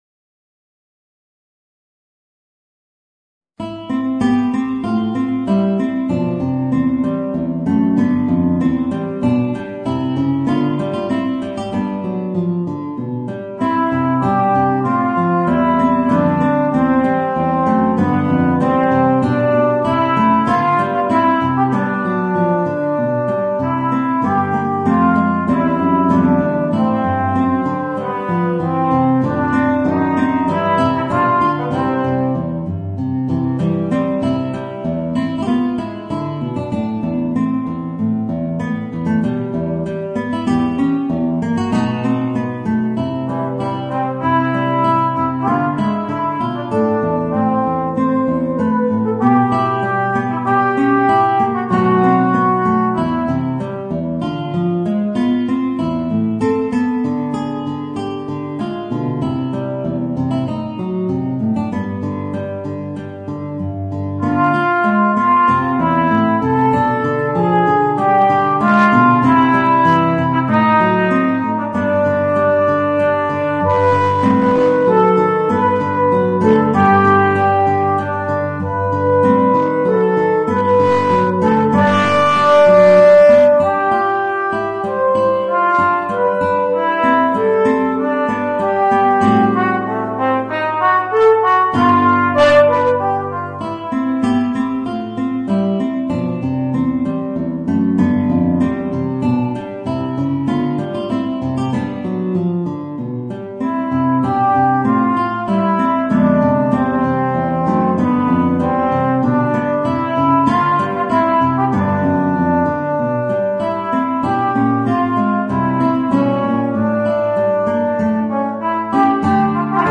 Voicing: Guitar and Alto Trombone